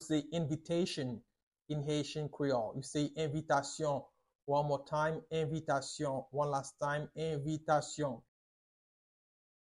Pronunciation:
Listen to and watch “Envitasyon” audio pronunciation in Haitian Creole by a native Haitian  in the video below: